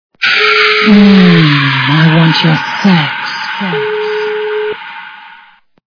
» Звуки » Люди фразы » Дьяволица - Fraza I Want Your Sex